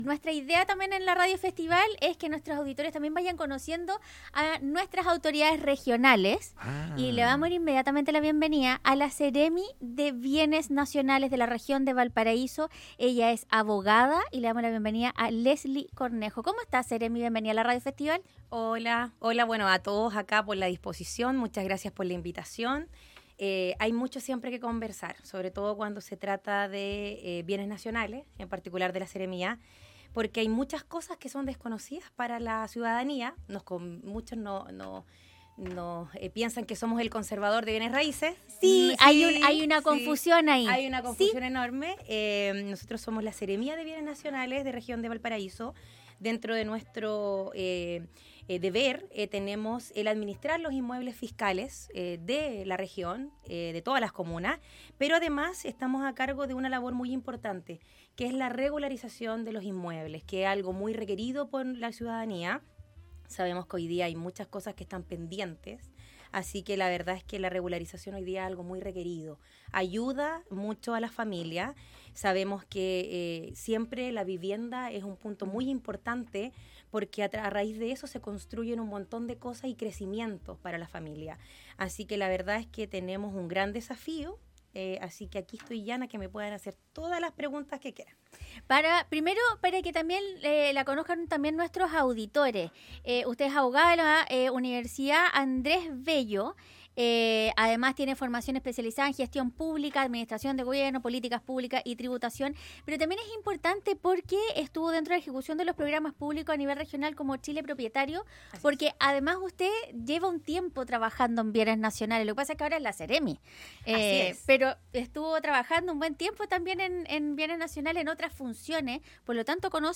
La abogada Leslie Cornejo estuvo en los estudios de la emisora para contar detalles de los trabajos que ya han comenzado a realizar a dos semanas de asumir en la cartera.